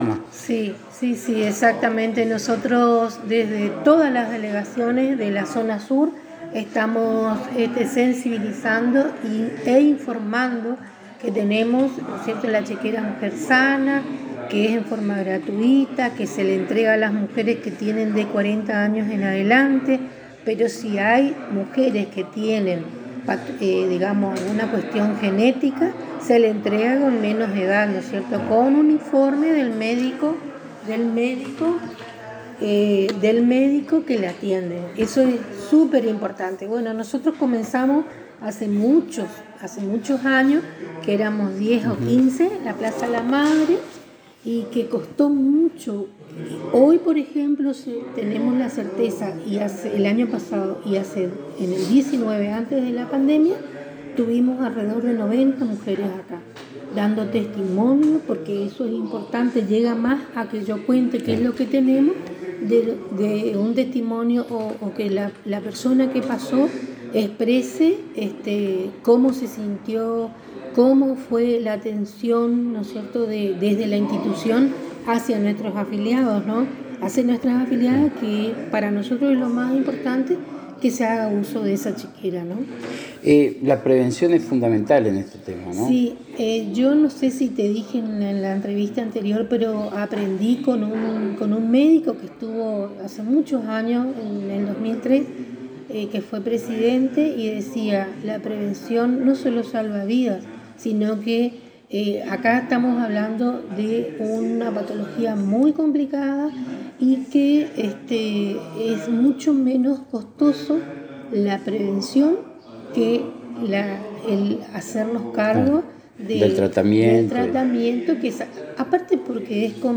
en diálogo exclusivo con la ANG